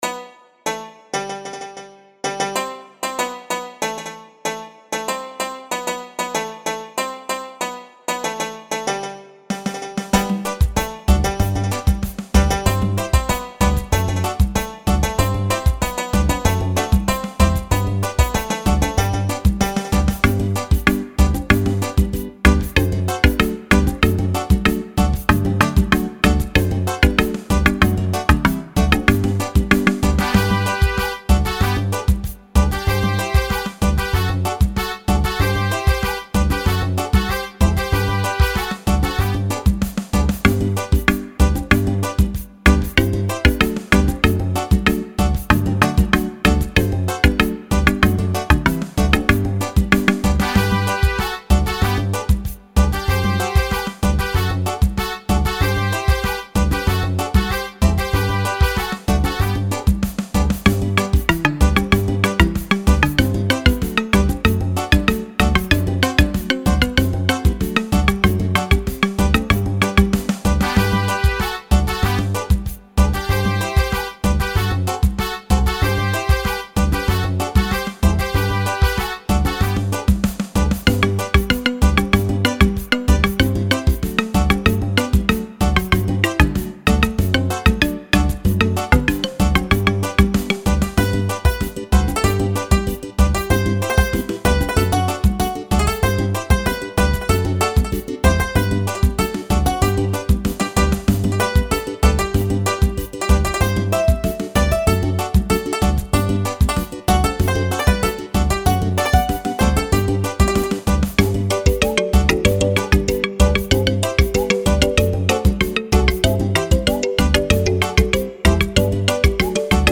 Brazil Folk . Up tempo version on Brazil marimba..